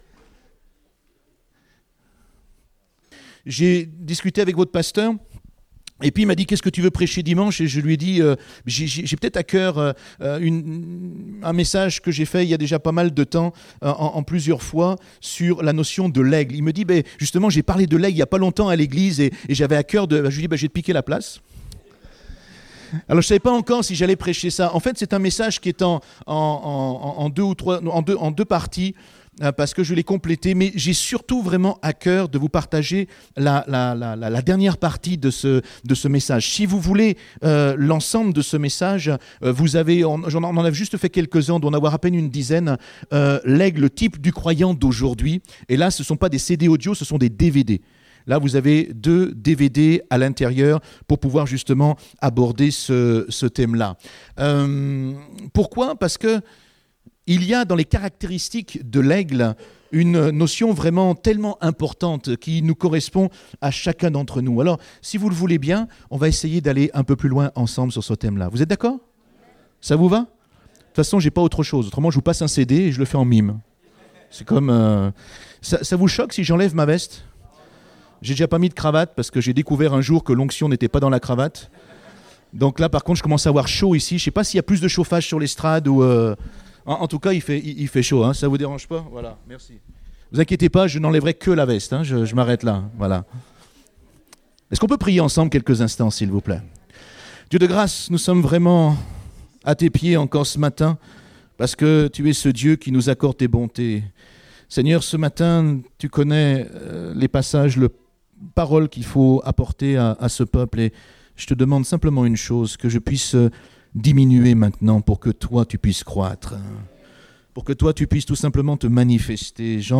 Date : 18 novembre 2018 (Culte Dominical)